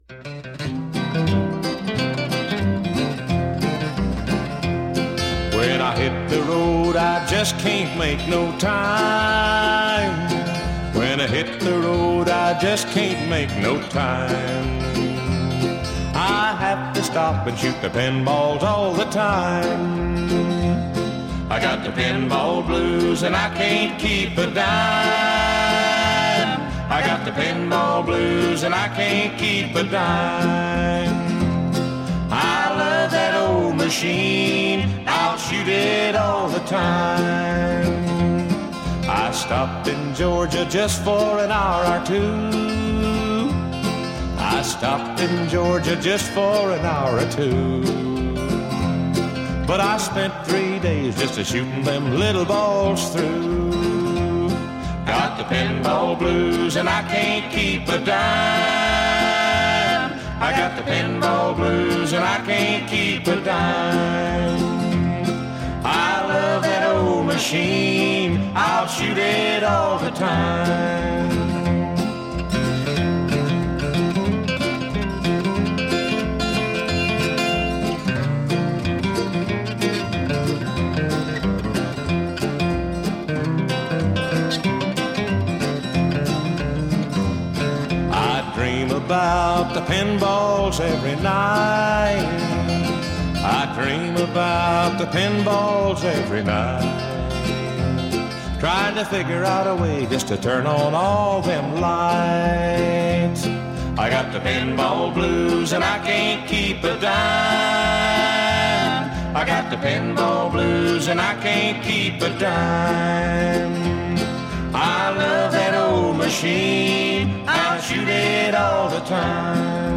Bluegrass
as a duo